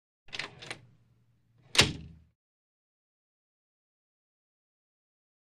HalfGlassWoodDoor4 PE181501
Half Glass / Wood Door 4; Opens And Closes Quickly.